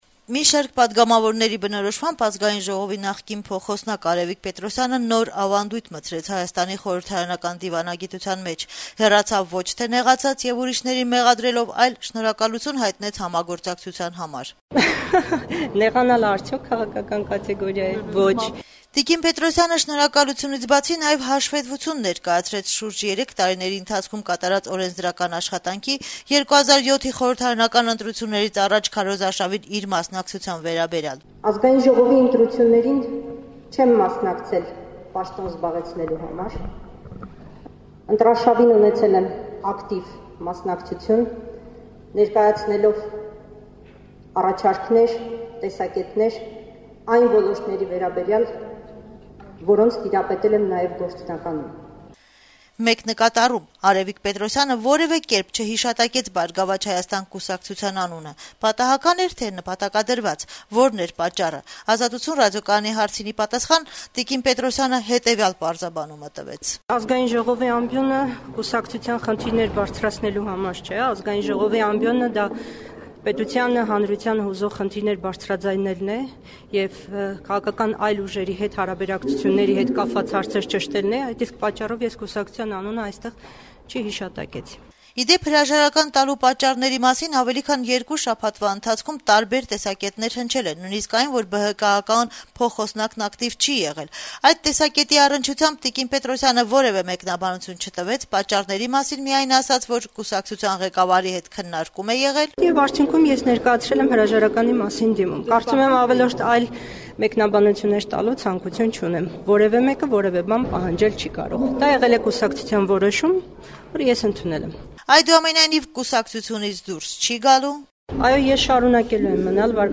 Ազգային ժողովի նախկին փոխնախագահ Արեւիկ Պետրոսյանը («Բարգավաճ Հայաստան») երկուշաբթի օրը լրագրողների հետ հանդիպմանը հրաժարվեց հավելյալ մեկնաբանություններ տալ իր հրաժարականի պատճառների մասին: